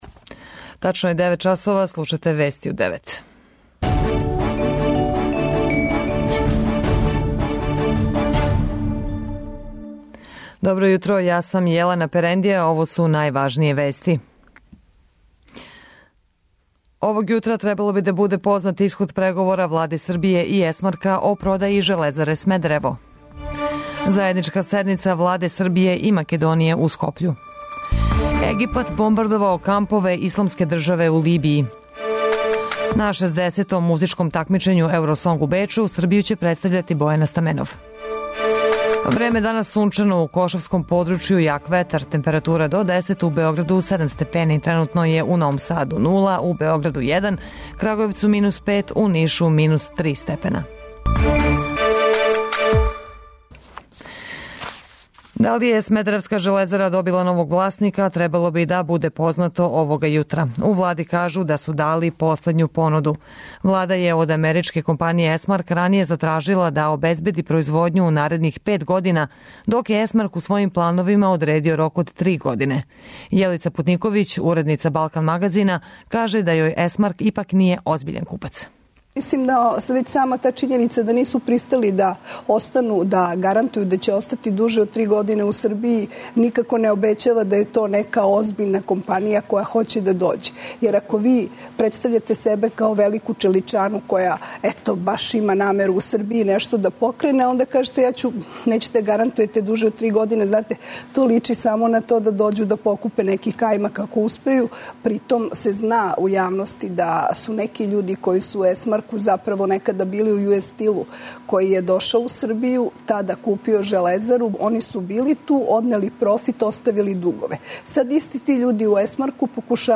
Вести у 9
преузми : 7.36 MB Вести у 9 Autor: разни аутори Преглед најважнијиx информација из земље из света.